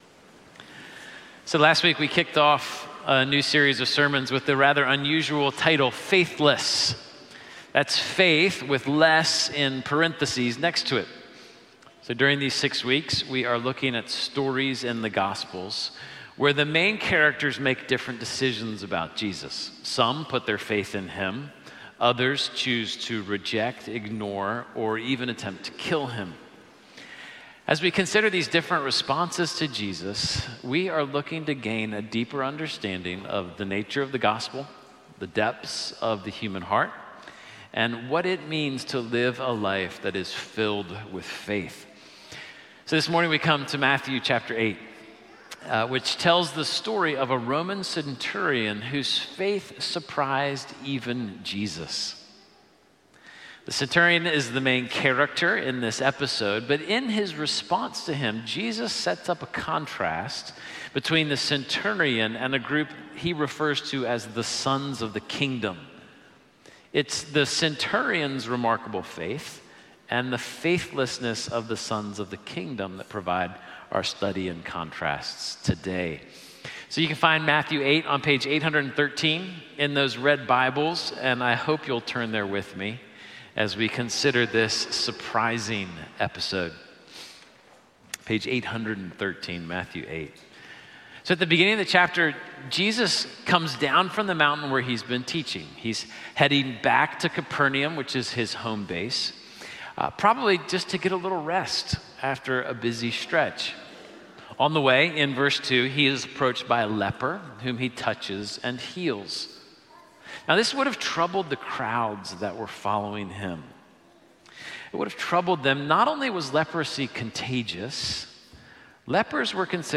1 Sermon: The Centurion & the Pharisees - Epiphany Series: Faith(less)